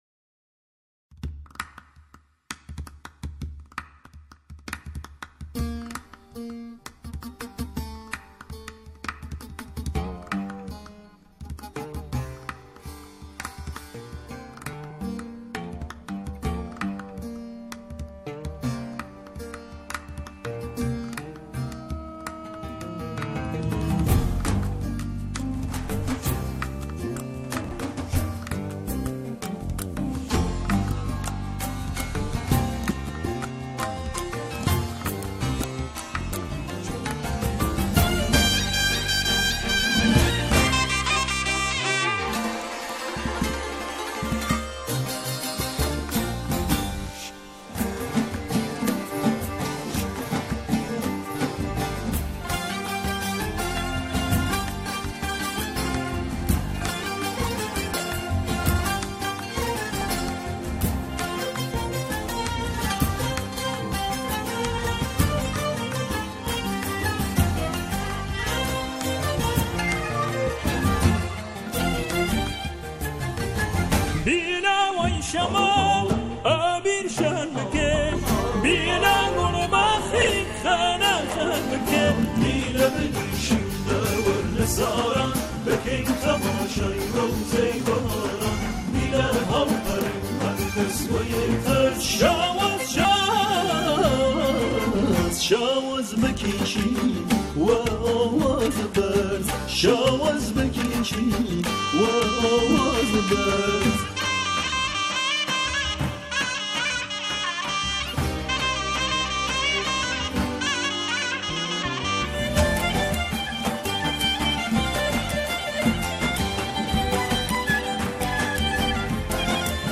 همخوانی شعری درباره “طبیعت”